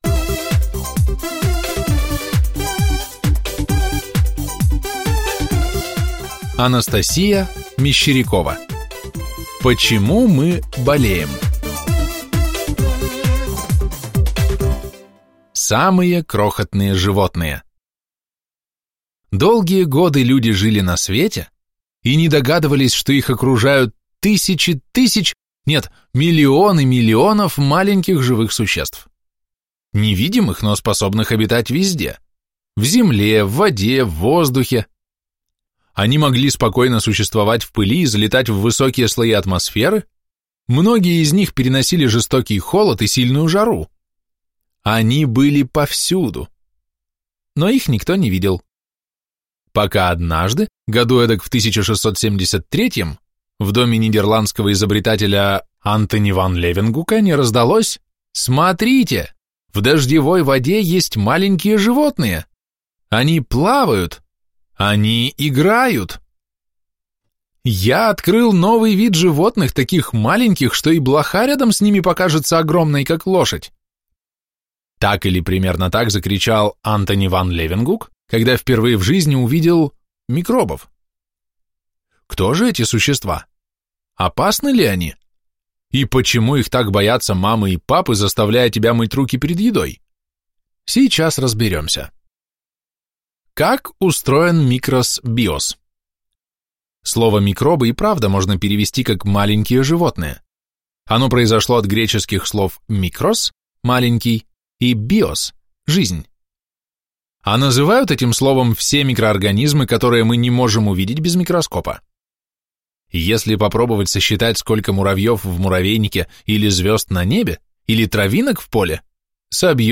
Аудиокнига Почему мы болеем?